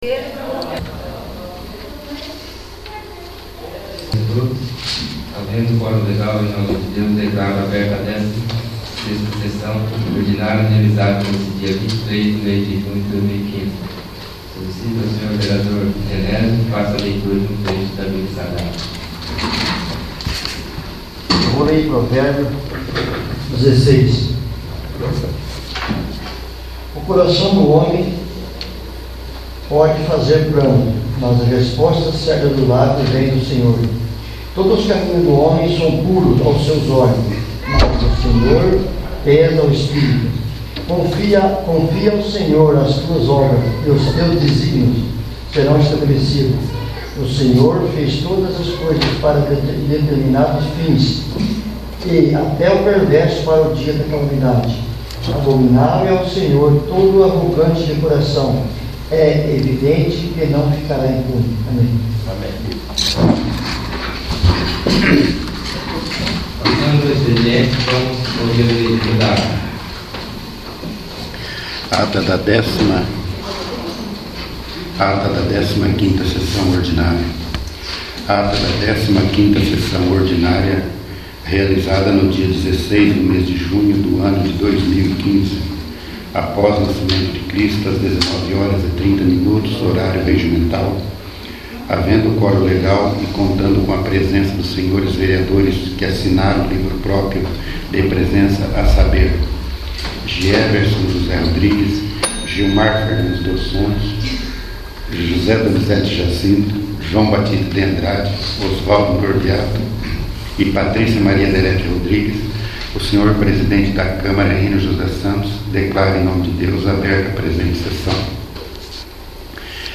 16º. Sessão Ordinária